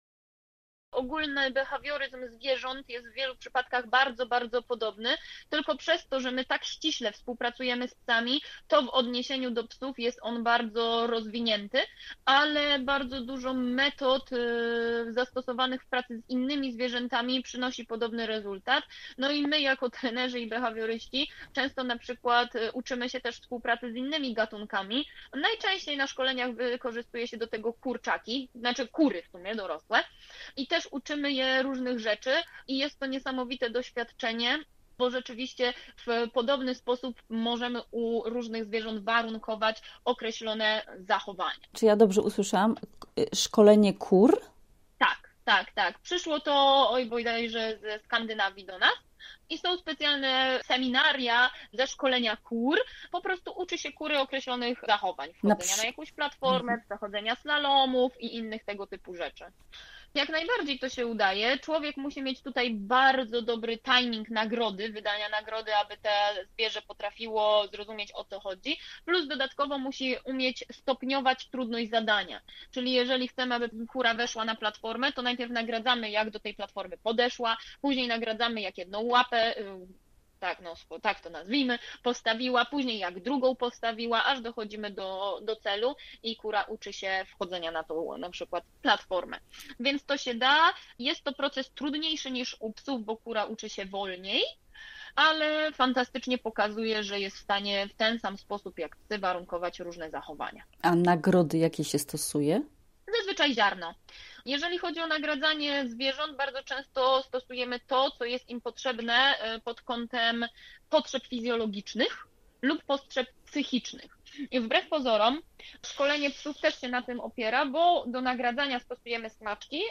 Rozmowy z trenerkami psów